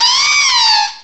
sovereignx/sound/direct_sound_samples/cries/servine.aif at 6b8665d08f357e995939b15cd911e721f21402c9